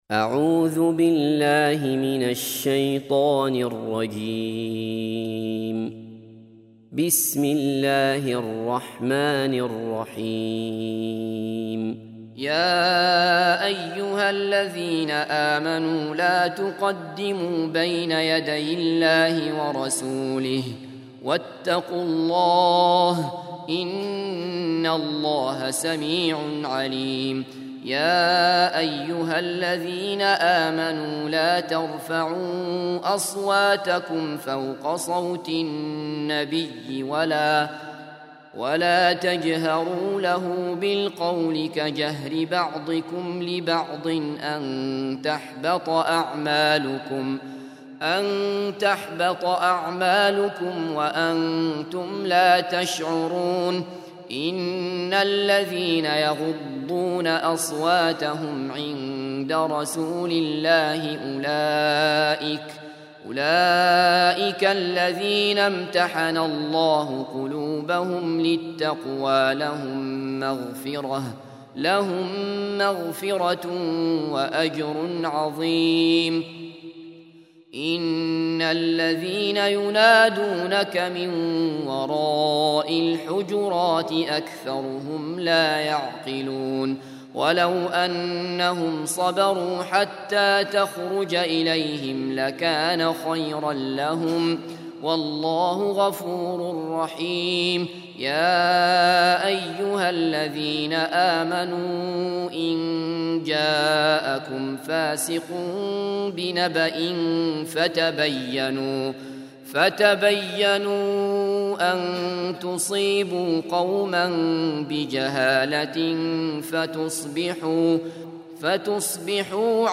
49. Surah Al-Hujur�t سورة الحجرات Audio Quran Tarteel Recitation
Surah Repeating تكرار السورة Download Surah حمّل السورة Reciting Murattalah Audio for 49.